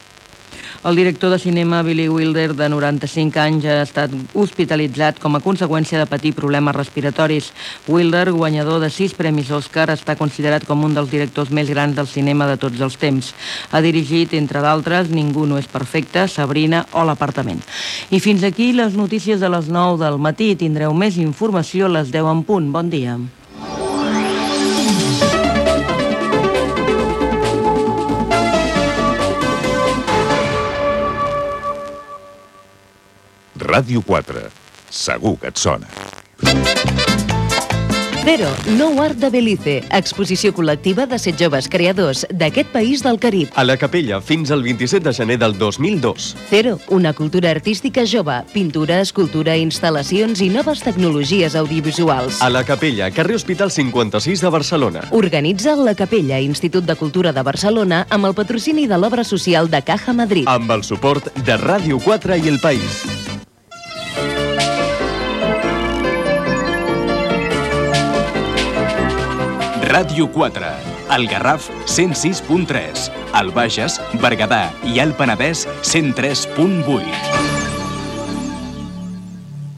Hospitalització del director de cinema Billy Wilder, indicatiu de l'emissora, publicitat, indicatiu de l'emissora i dues de les freqüències d'emissió
Informatiu